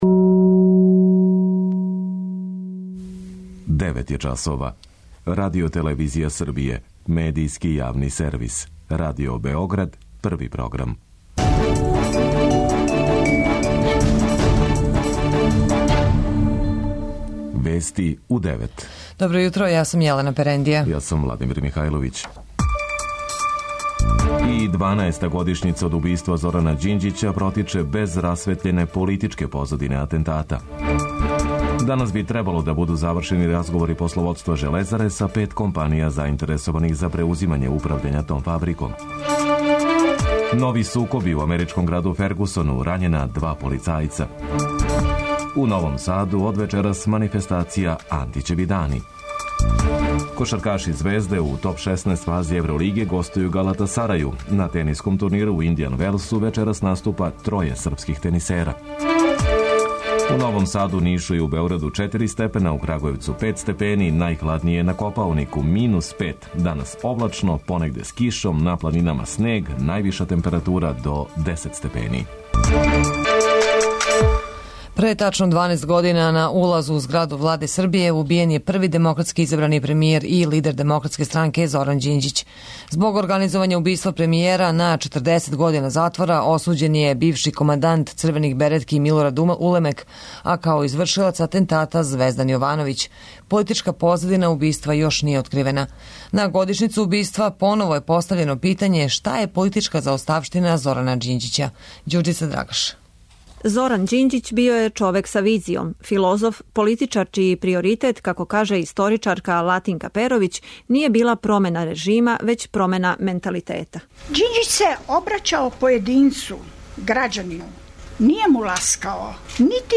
Највиша температура до 10 степени. преузми : 10.02 MB Вести у 9 Autor: разни аутори Преглед најважнијиx информација из земље из света.